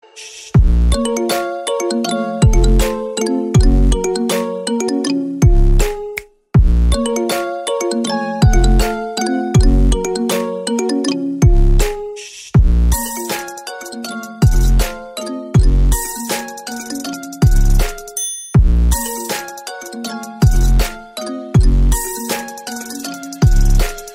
Effets Sonores